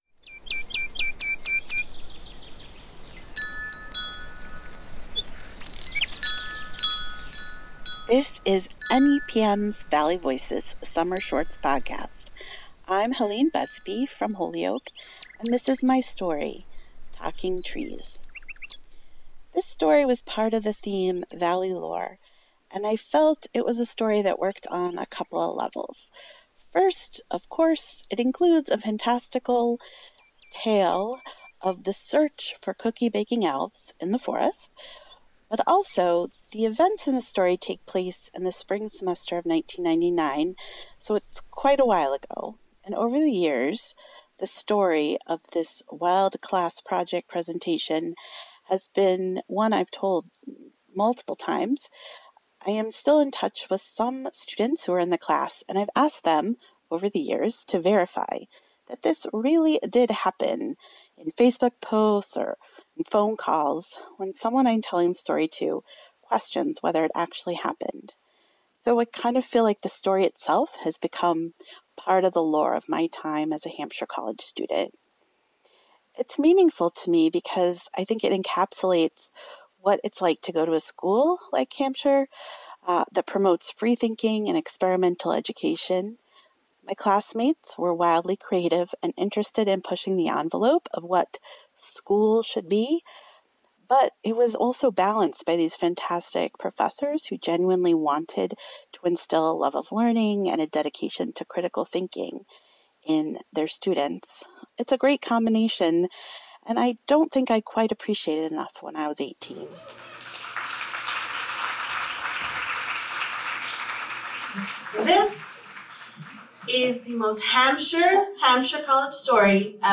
Our podcast features the outrageous, funny and heartwarming true stories told by your friends and neighbors at Valley Voices Story Slam events throughout western Massachusetts.